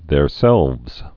(thâr-sĕlvz) also their·self (-sĕlf)